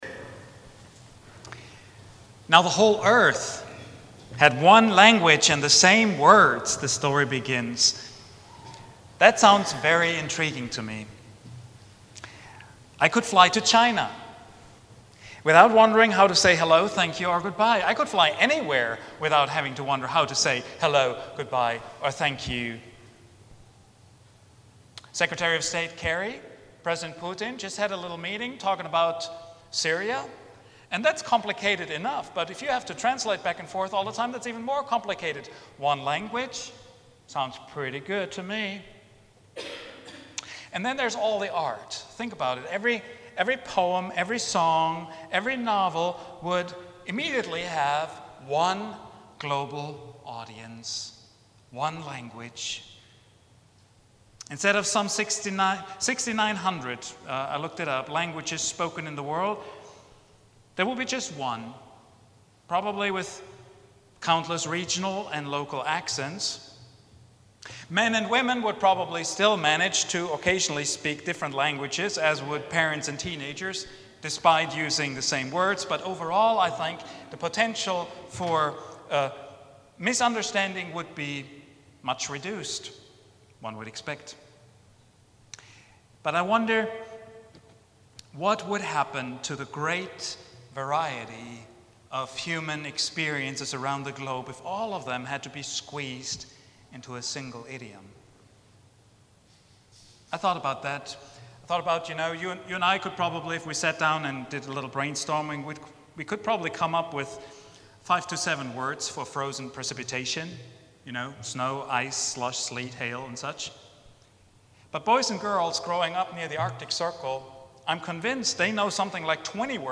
God's City Project — Vine Street Christian Church